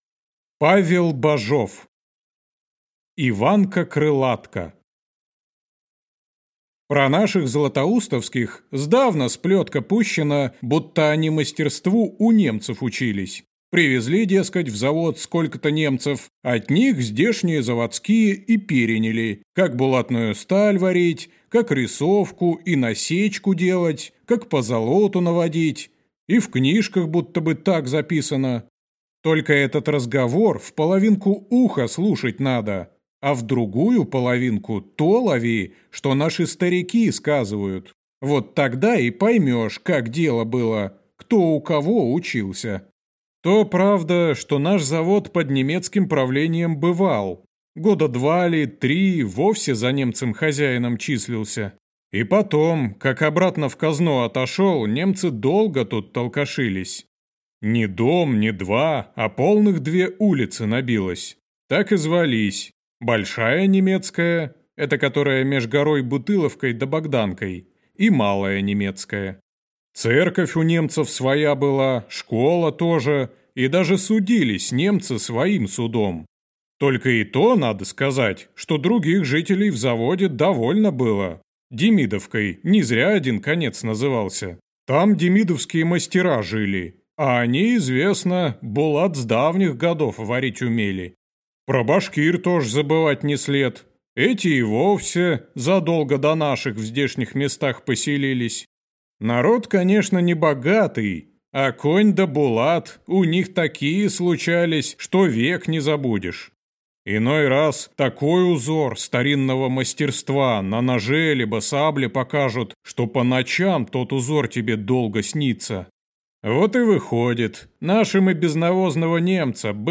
Аудиокнига Иванко Крылатко | Библиотека аудиокниг